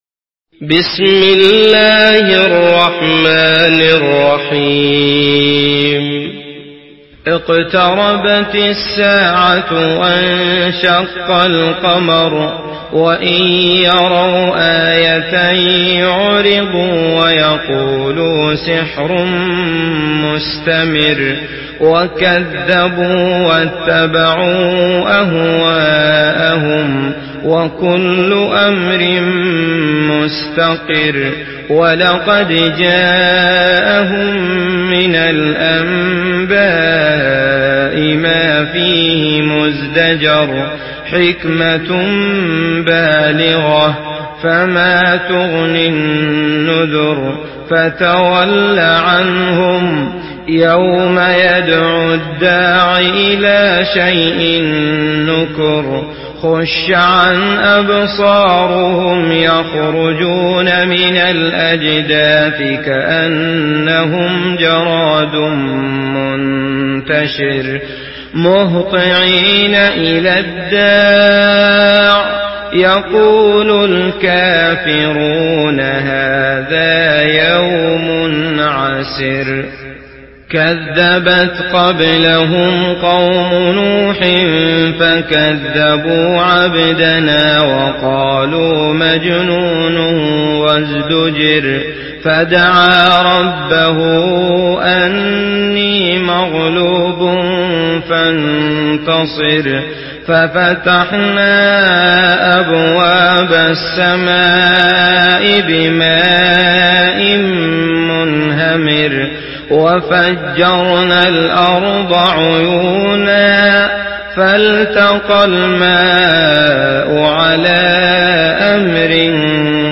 Surah Kamer MP3 by Abdullah Al Matrood in Hafs An Asim narration.
Murattal Hafs An Asim